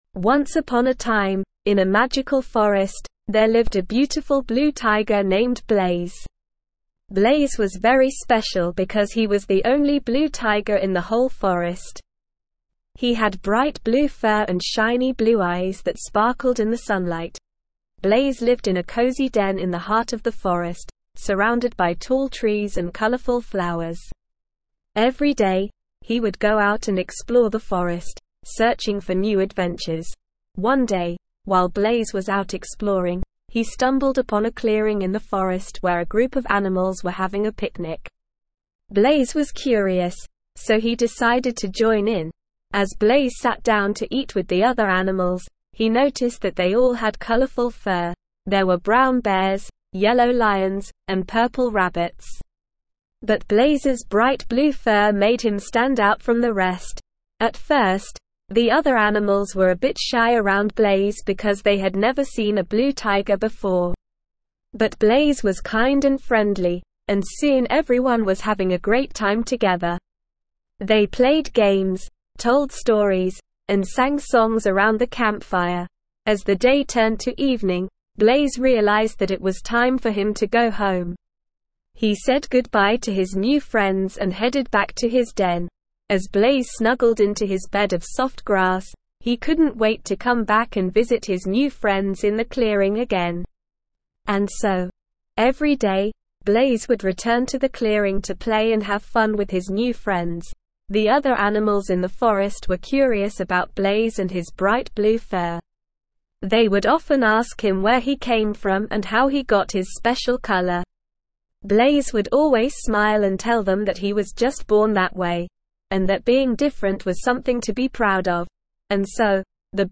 Slow
ESL-Short-Stories-for-Kids-SLOW-reading-Blaze-the-Blue-Tiger.mp3